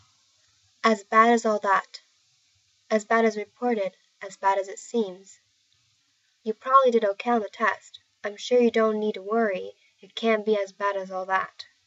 英語ネイティブによる発音は下記をクリックしてください。